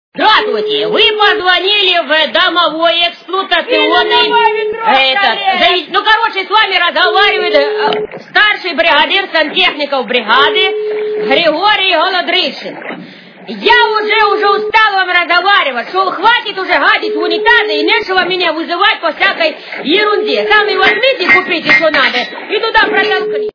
При прослушивании Автоответчик - Добрый усталый сантехник Голодрищенко качество понижено и присутствуют гудки.